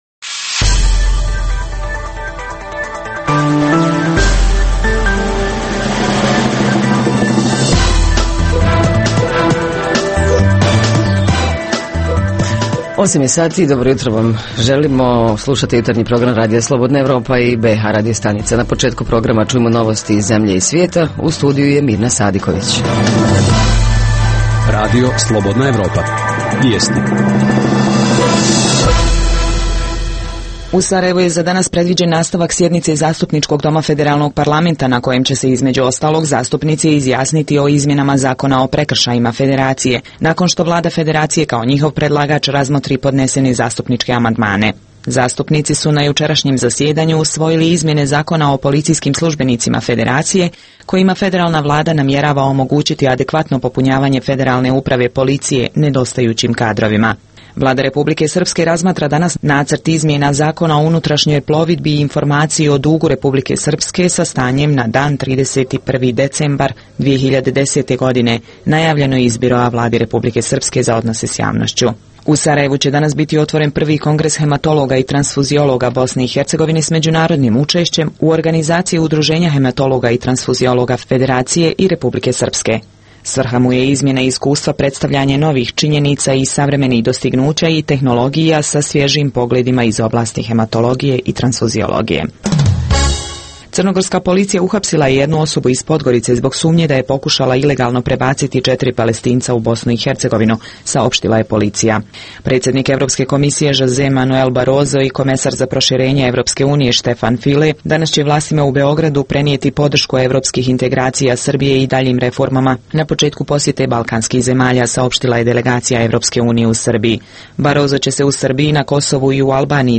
- Reporteri iz cijele BiH javljaju o najaktuelnijim događajima u njihovim sredinama.
- Redovni sadržaji jutarnjeg programa za BiH su i vijesti i muzika.